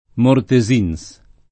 [ morte @& n S ]